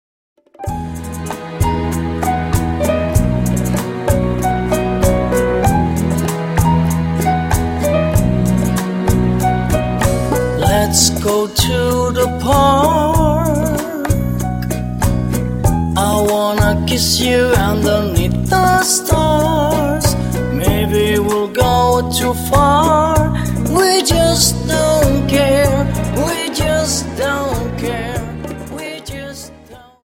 Dance: Rumba Song